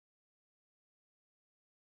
موسیقی احساسی و عربسک ترکیه‌ای